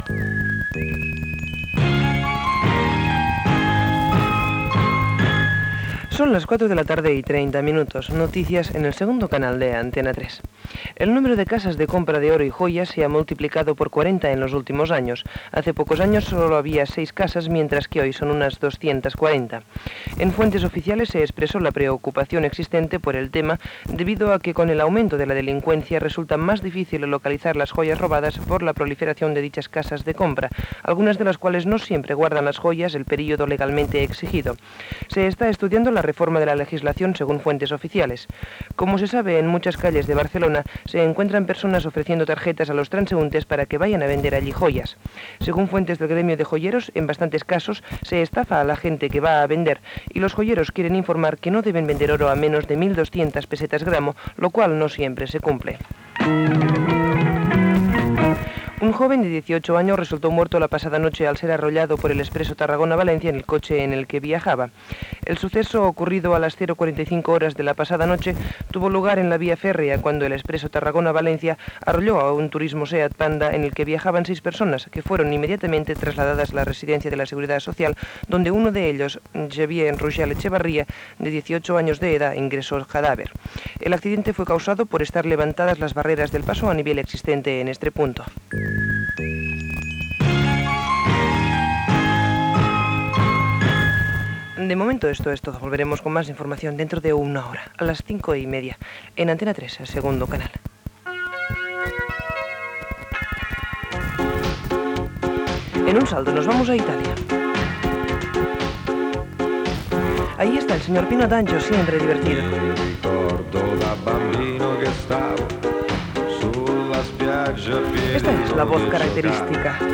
Indicatiu, hora i notícies: cases de compra-venda de joies, accident de tren.
Informatiu
FM